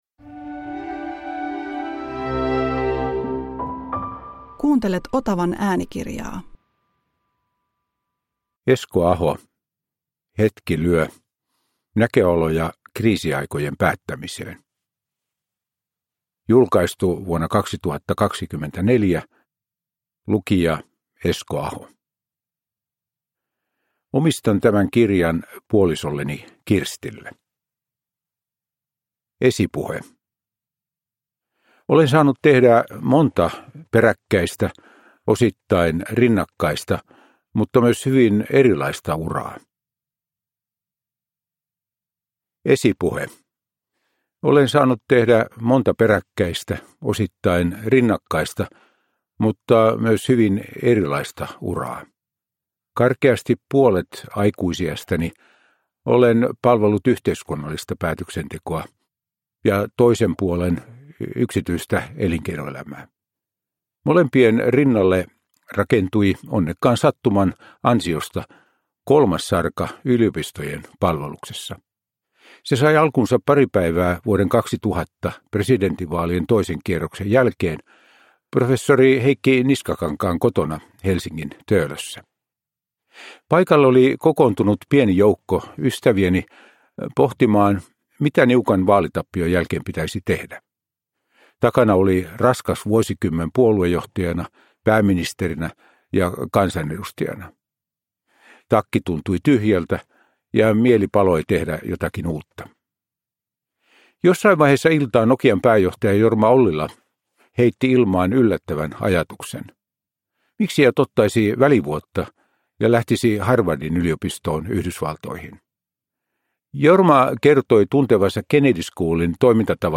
Hetki lyö – Ljudbok
Uppläsare: Esko Aho